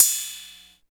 39 OP HAT.wav